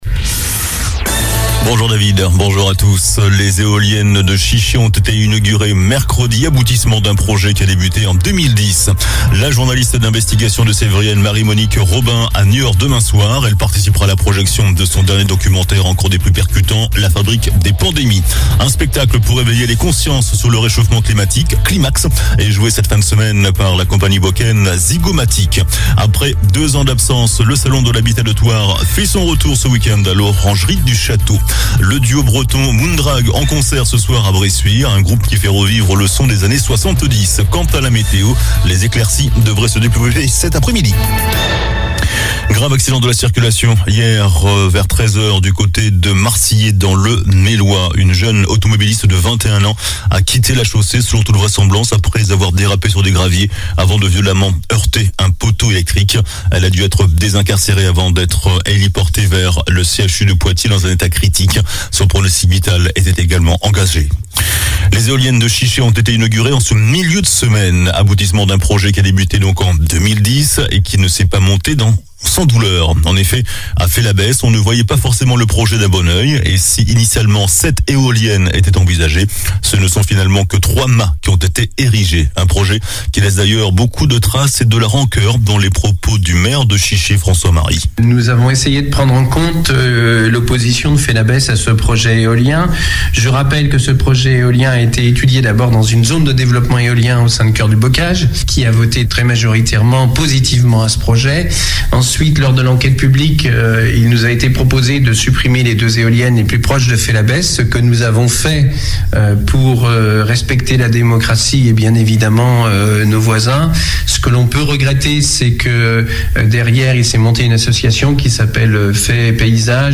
JOURNAL DU VENDREDI 20 MAI ( MIDI )